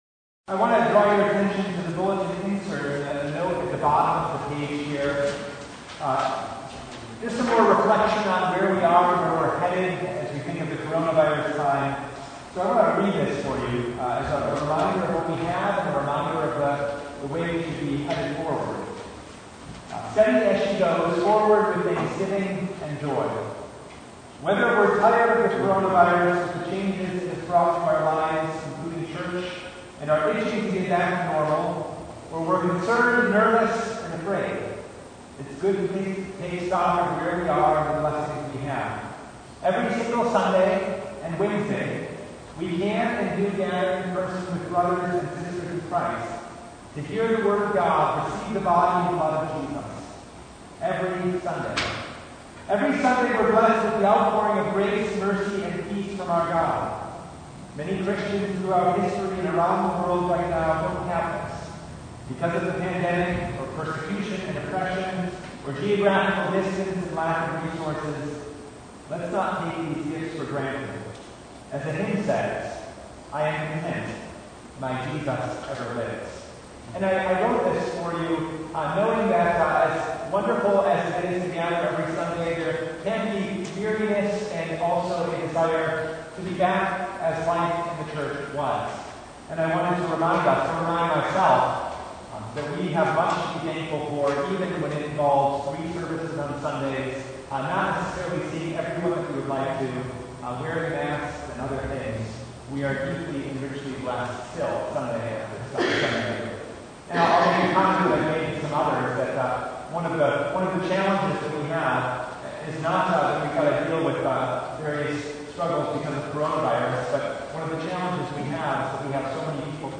Isaiah 25:6-9 Service Type: Sunday Jesus died and rose again and so swallowed up death forever!